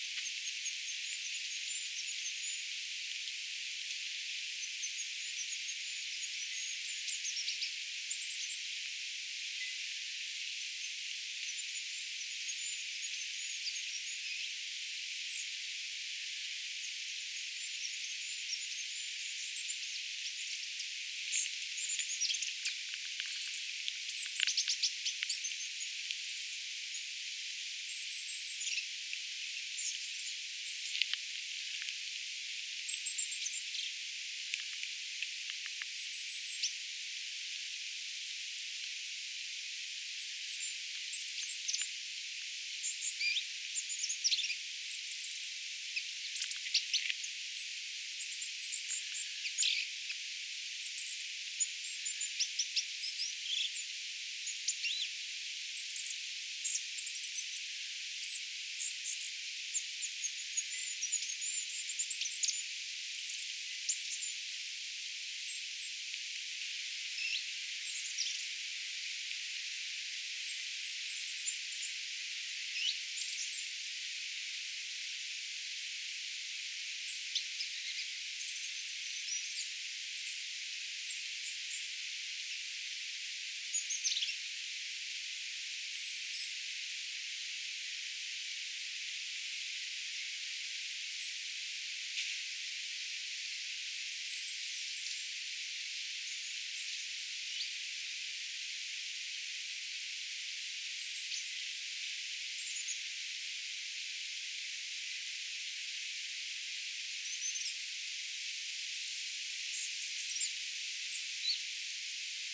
aika mielenkiintoista hippiäisen ääntelyä, laulua
Se lauloi aluksi oikein kunnolla.
aika_mielenkiintoista_hippiaisen_aantelya_laulua.mp3